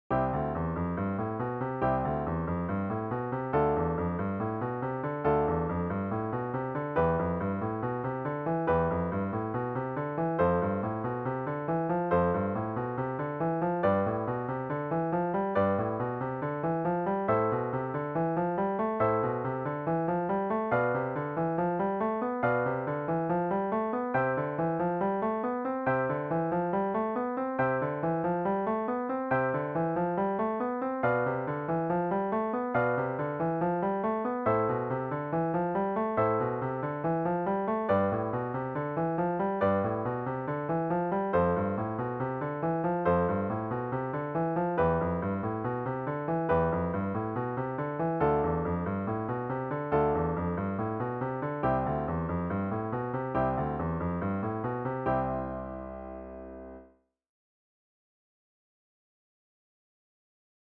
🎹 Piano Technique Exercise